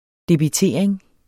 Udtale [ debiˈteˀɐ̯eŋ ]